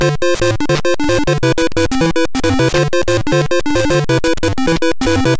Self-captured from the Sharp X1 version